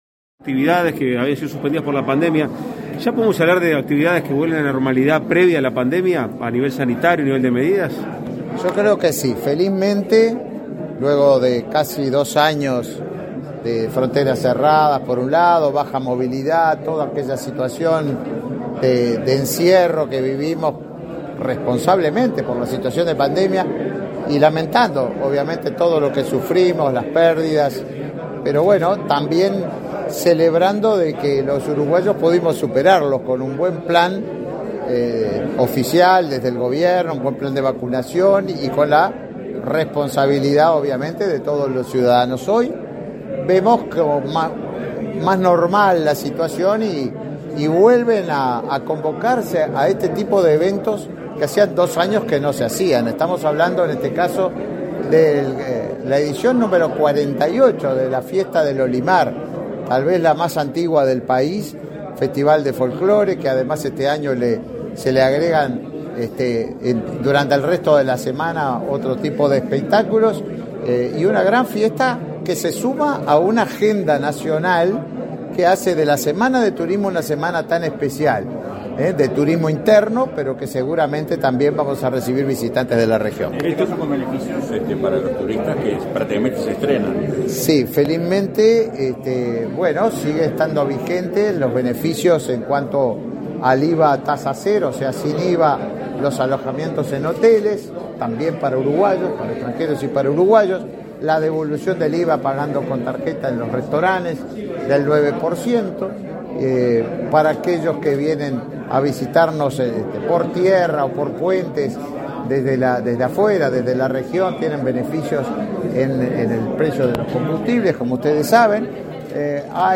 Declaraciones a la prensa del ministro de Turismo, Tabaré Viera
Declaraciones a la prensa del ministro de Turismo, Tabaré Viera 22/03/2022 Compartir Facebook X Copiar enlace WhatsApp LinkedIn Tras participar en el lanzamiento de la 48.ª Edición del Festival del Olimar, este 22 de marzo, el ministro de Turismo efectuó declaraciones a la prensa.